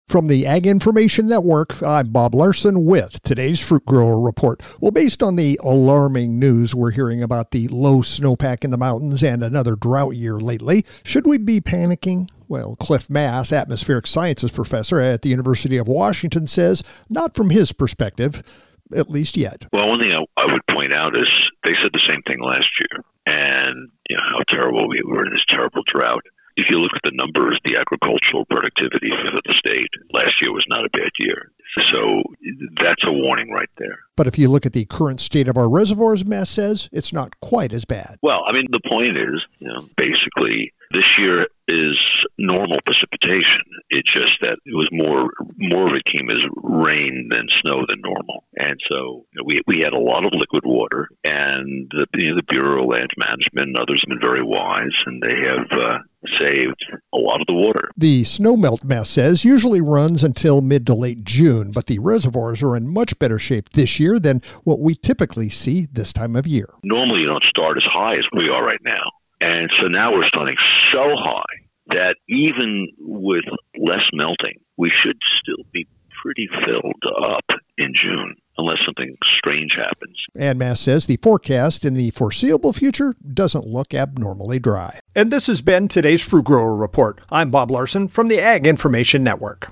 Tuesday Apr 21st, 2026 37 Views Fruit Grower Report